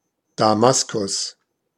Ääntäminen
Synonyymit Offenbarung Erscheinung Ääntäminen Tuntematon aksentti: IPA: /daˈmaskʊs/ Haettu sana löytyi näillä lähdekielillä: saksa Käännös Erisnimet 1.